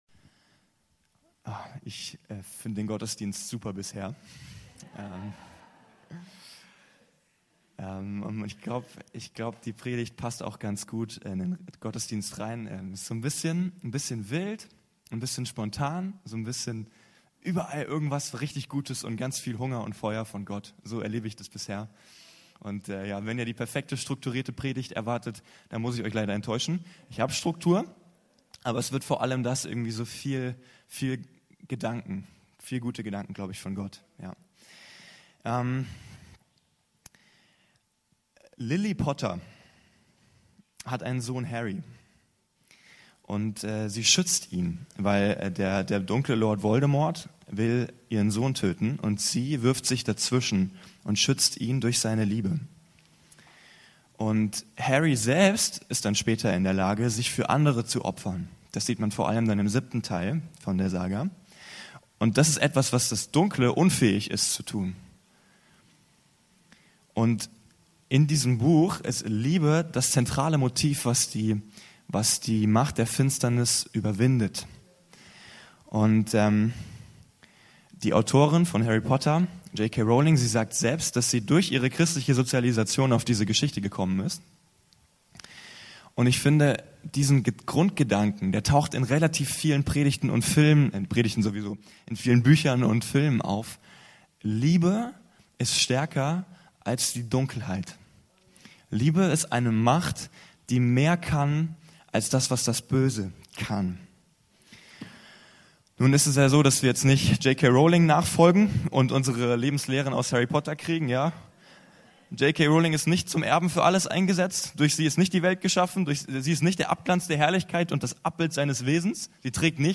Eine Predigt über Liebe, das Gesetz des Mose, und ewiges Leben.